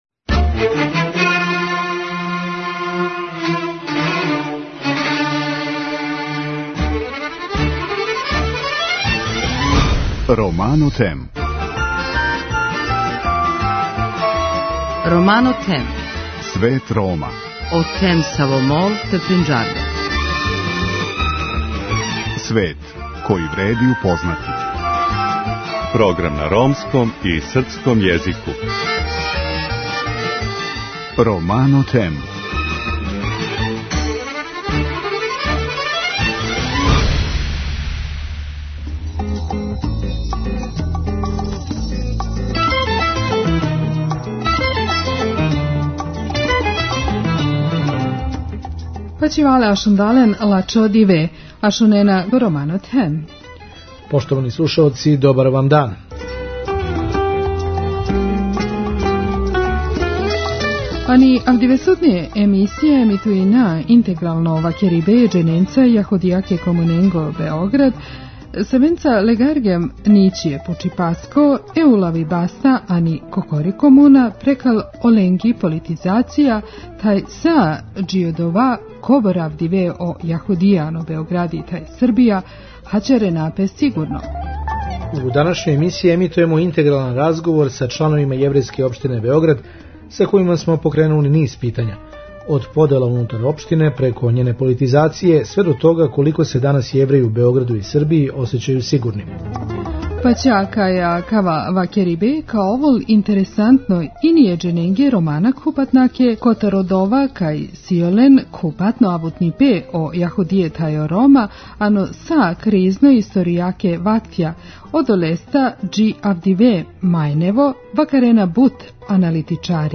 У оквиру летње шеме данас поново емитујемо разговор са истакнутим члановима Јеврејске општине у Београду.